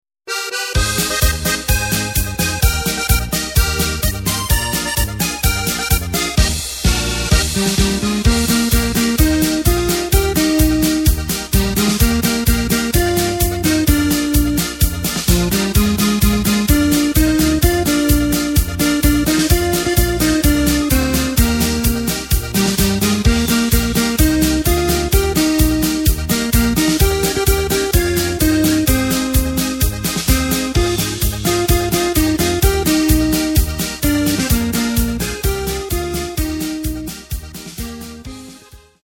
Takt:          4/4
Tempo:         128.20
Tonart:            Eb
Schweizer MarschLied!